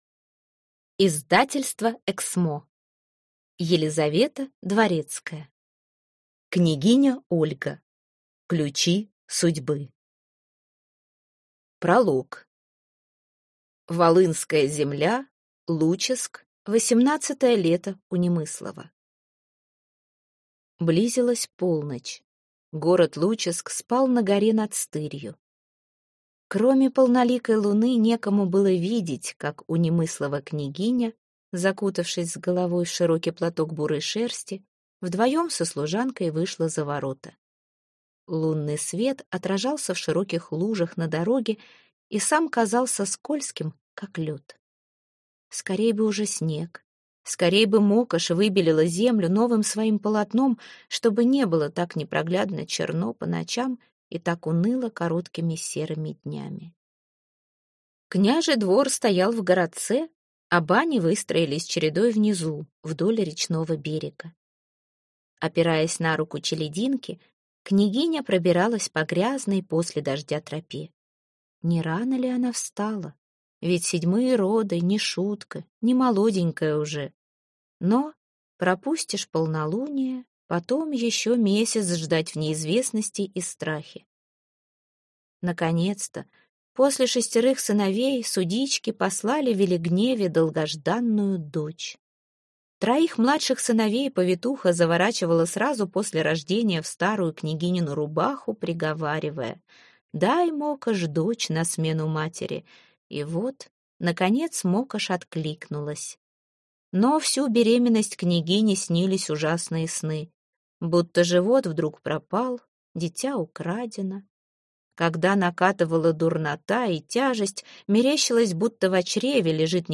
Аудиокнига Княгиня Ольга.